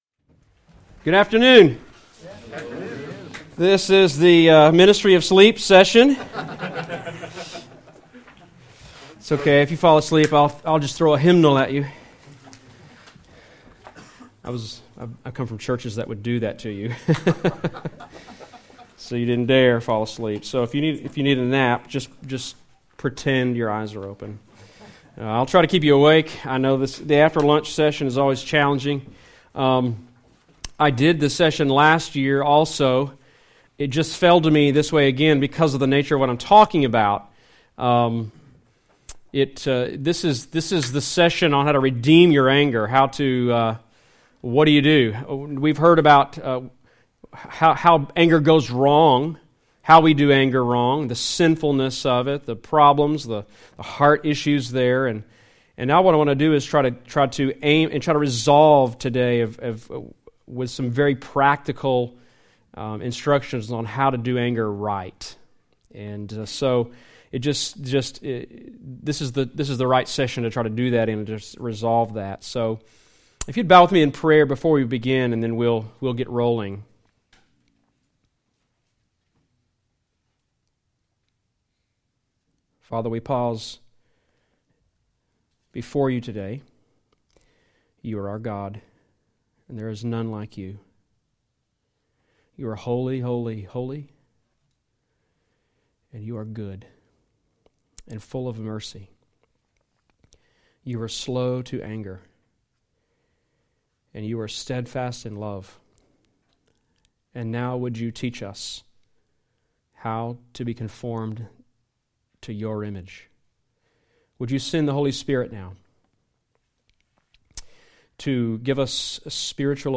Play the sermon Download Audio ( 31.46 MB ) Email Session 4 - Redeem Your Anger Details Series: General Topics Date: 2017-03-04 Scripture : Ephesians 4:26-27